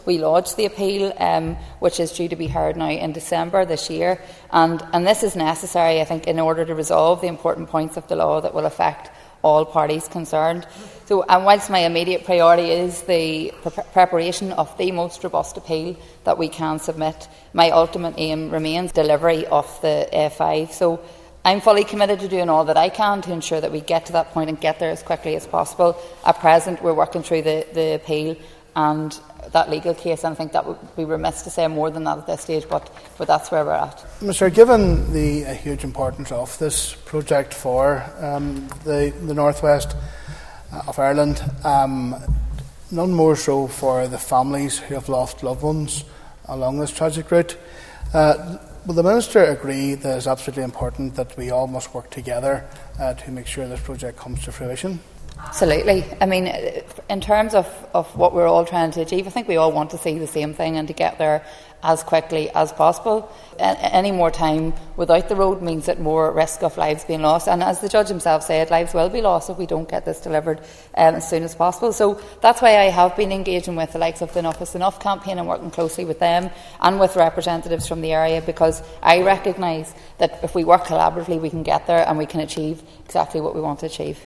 Minister Liz Kimmins was answering questions in the Assembly from West Tyrone MLA Declan McAleer, who says the application by Agriculture Minister Andrew Muir for an intervention to support the A5 appeal is also a welcome step.
Minister Kimmins told the Assembly delivering the A5 remains a top priority for her Department……..